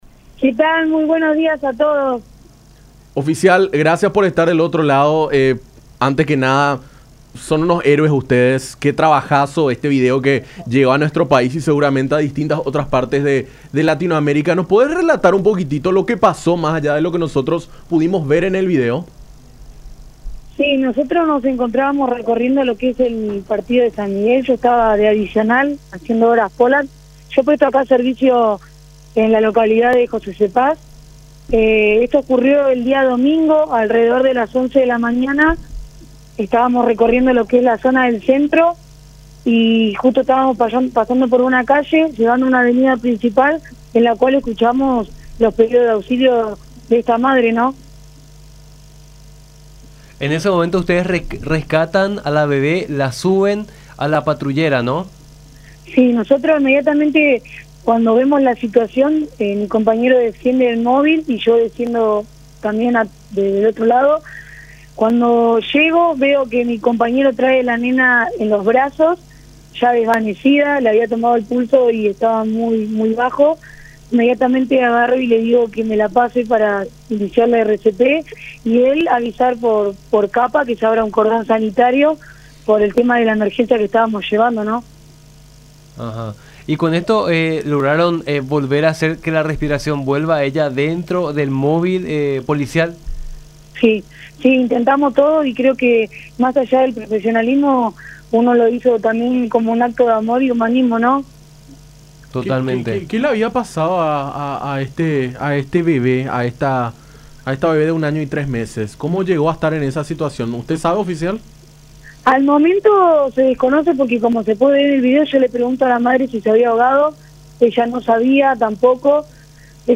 en diálogo con Nuestra Mañana por La Unión.